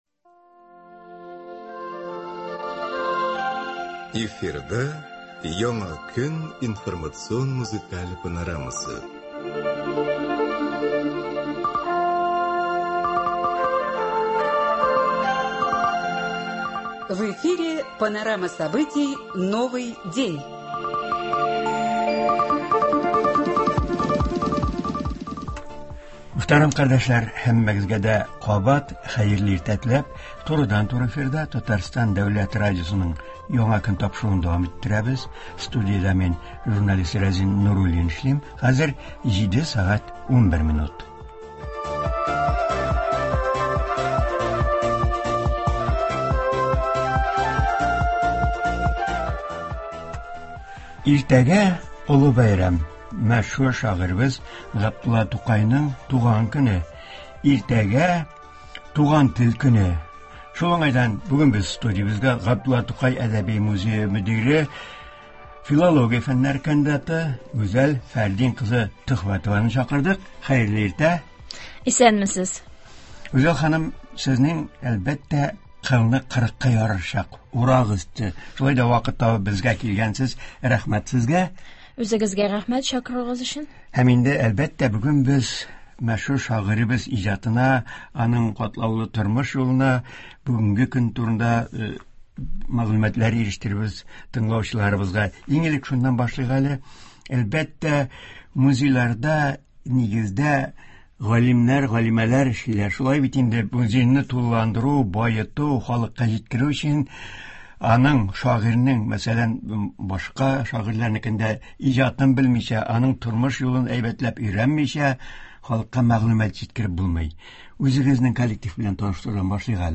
Туры эфир (25.04.22)